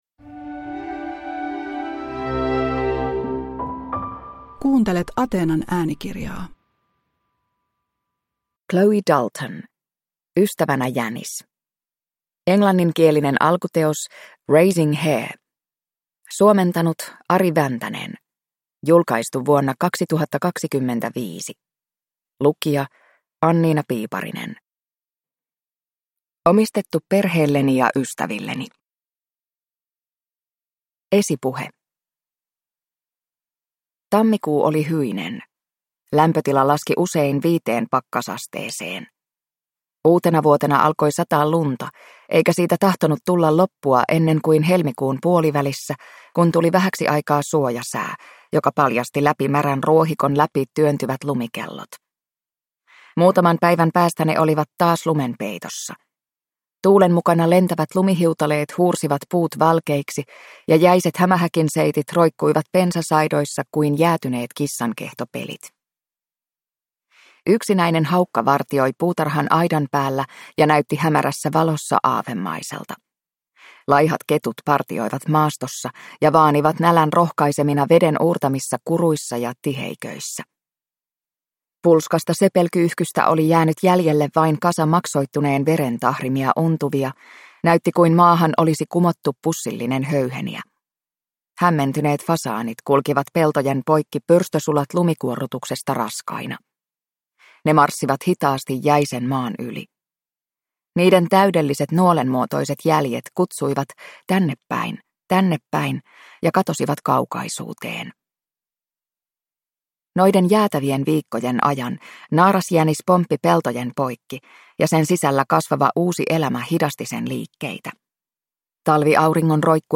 Ystävänä jänis – Ljudbok